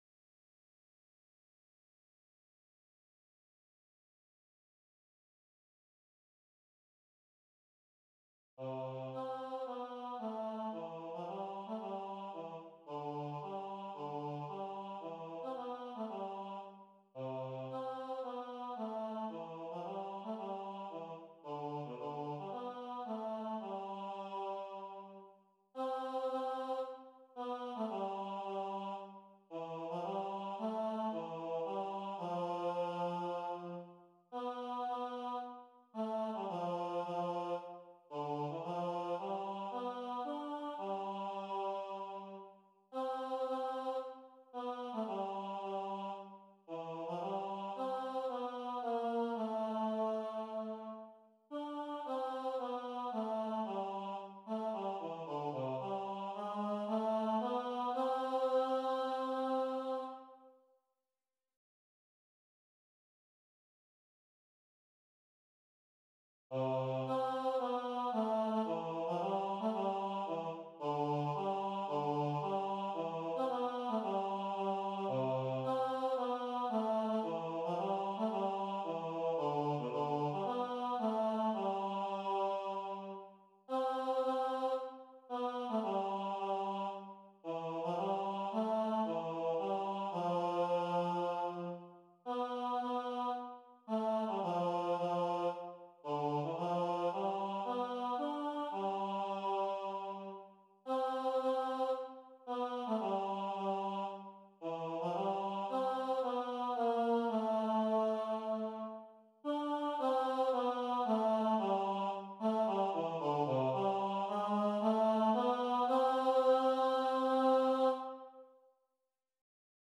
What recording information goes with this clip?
For church performances.